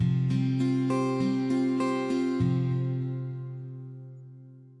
这些样本是使用各种硬件和软件合成器以及外部第三方效果创建的。
Tag: 声学 和弦 声和弦 音乐为主-ON-最终幻想 样品